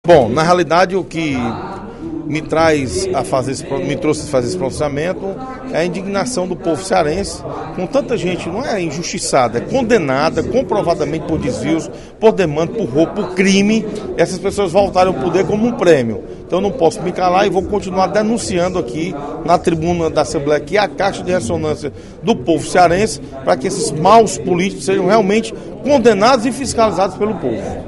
O deputado Osmar Baquit (PSD) demonstrou, nesta terça-feira (03/07) em sessão plenária, indignação com as candidaturas e o retorno de políticos presos condenados “comprovadamente” por desvio de recursos e vários outros crimes.